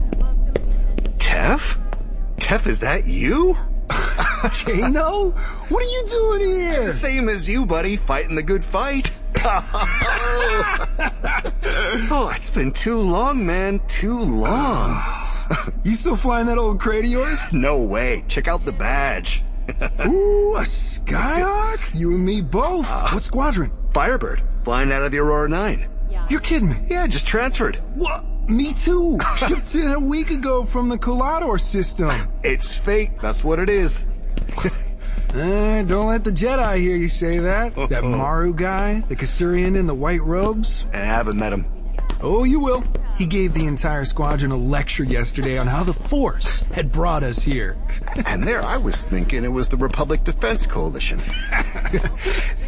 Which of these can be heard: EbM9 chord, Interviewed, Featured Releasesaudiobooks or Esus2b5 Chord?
Featured Releasesaudiobooks